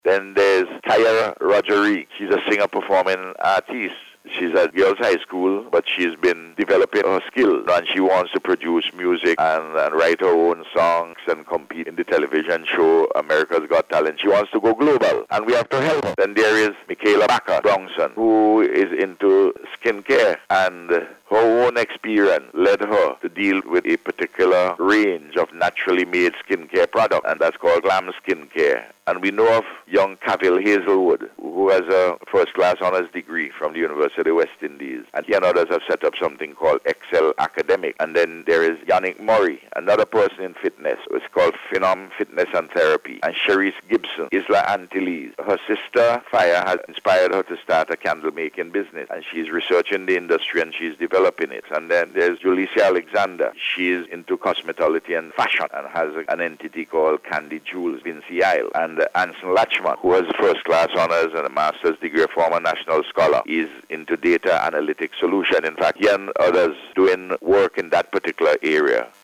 Dr. Gonsalves, who was speaking on NBC Radio Face to Face Program this morning, said it is a wonderful thing to highlight the work of these young people.